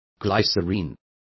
Complete with pronunciation of the translation of glycerin.